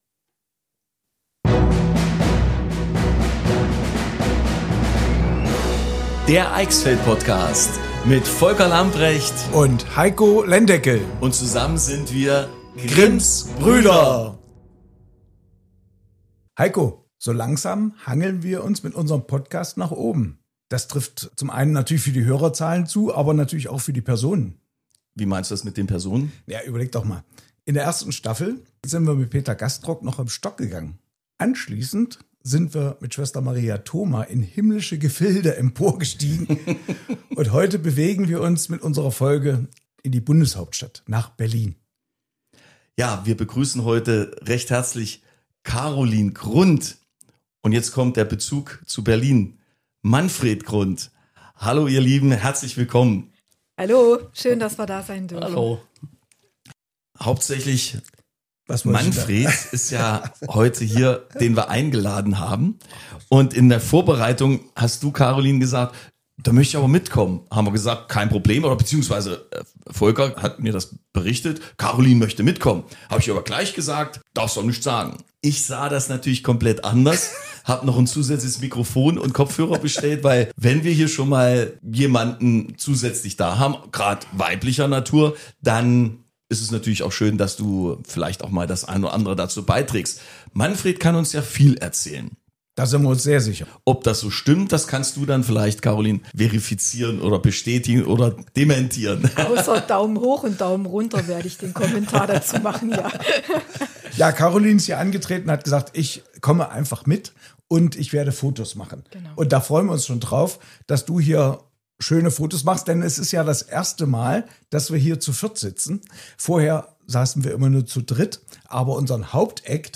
30 Jahre Bundestag – Ein Blick hinter die Kulissen In dieser Episode von Grimms Brüder – der Eichsfeld-Podcast sprechen wir mit Manfred Grund, der das Eichsfeld über drei Jahrzehnte im Deutschen Bundestag vertreten hat.